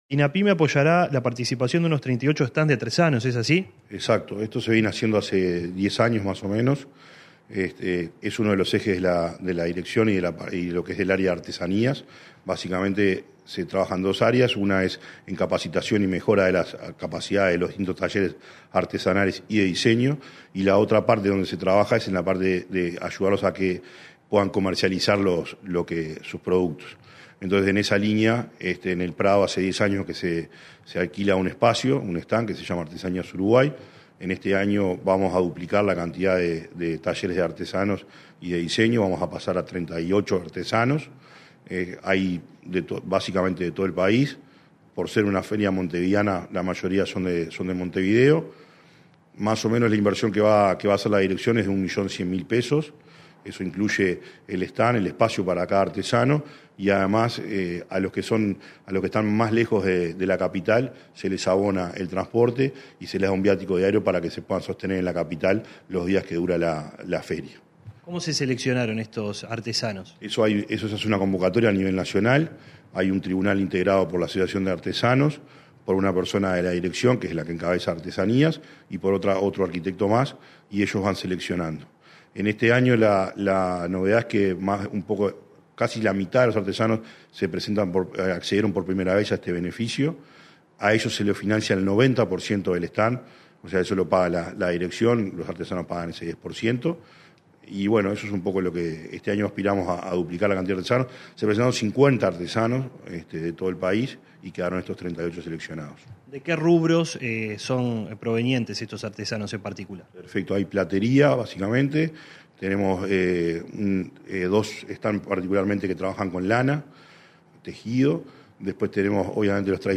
Entrevista al director de la Dinapyme, Gonzalo Maciel
El director nacional de la Dinapyme, Gonzalo Maciel, destacó la iniciativa, en declaraciones a Comunicación Presidencial.